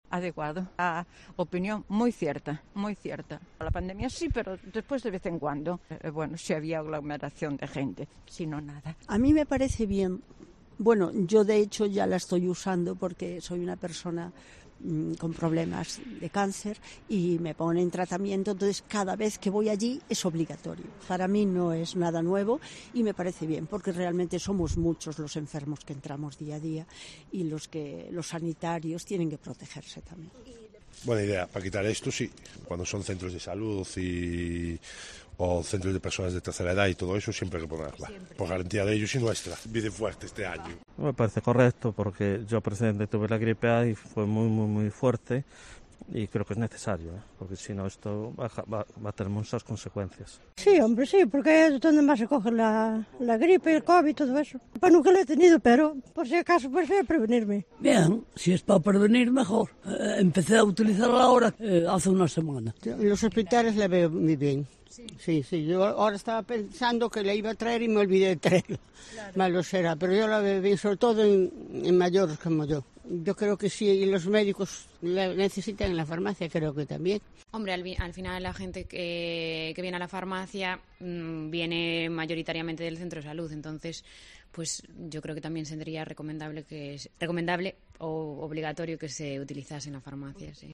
Los ferrolanos opinan sobre la obligatoriedad de las mascarillas en los centros de salud
Hoy salimos a la calle para preguntaros precisamente eso: ¿ es bienvenida la norma de nuevo de la obligatoriedad de portar mascarillas en estos entornos ?
Hay otras personas que lo hacen ya no para no contagiarse, sino cuando están enfermos, para proteger a sus amigos y familiares, esto nos comentó un ciudadano estadounidense que encontramos por las calles de Ferrol.